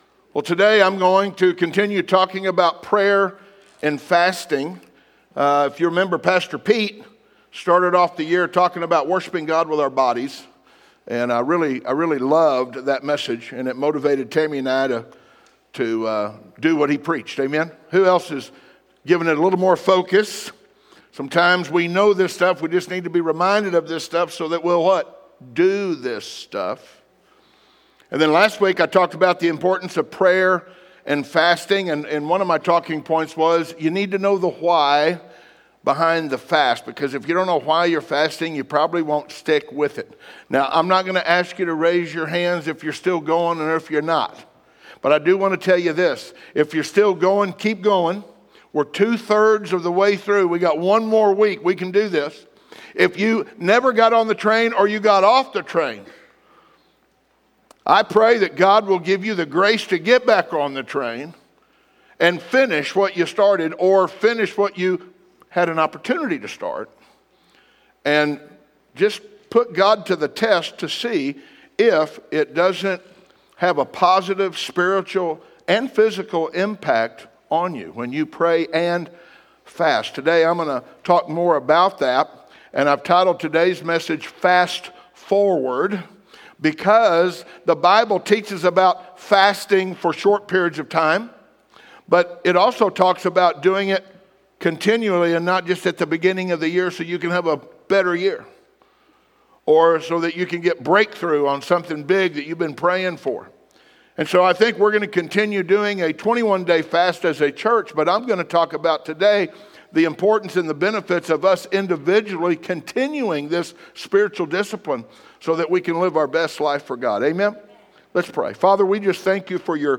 In this week’s message